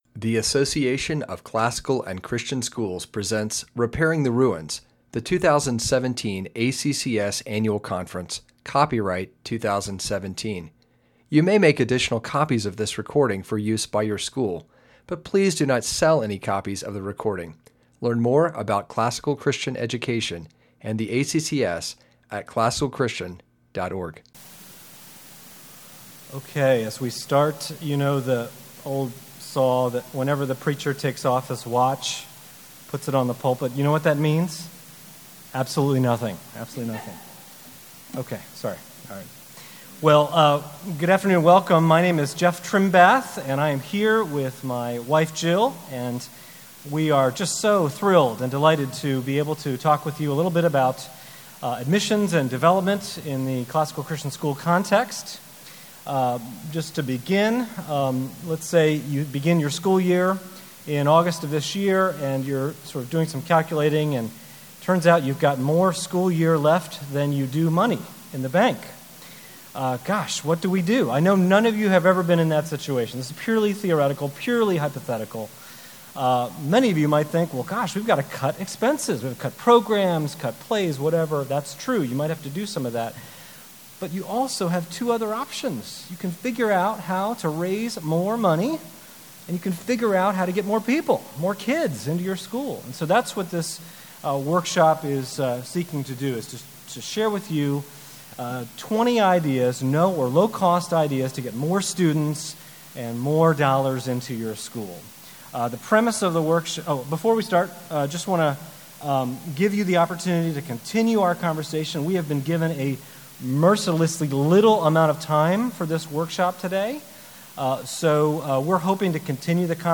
2017 Leaders Day Talk | 0:49:56 | Fundraising & Development, Marketing & Growth